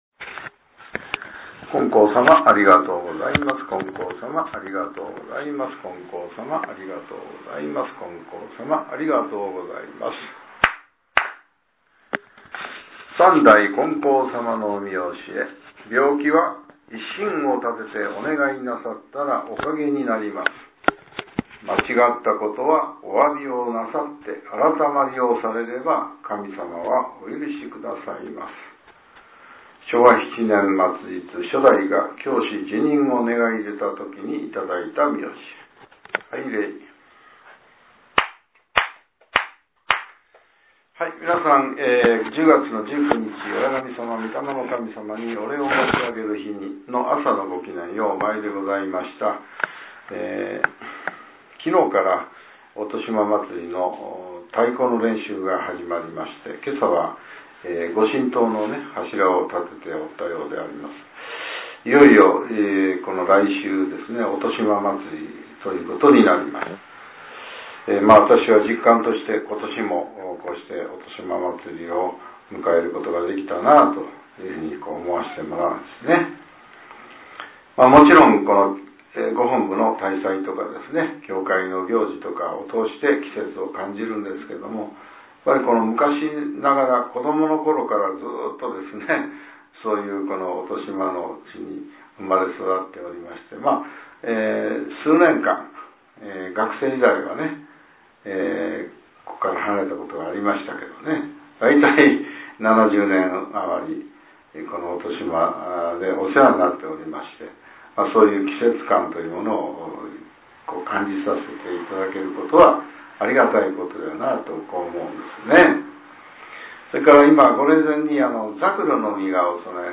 令和７年１０月１９日（朝）のお話が、音声ブログとして更新させれています。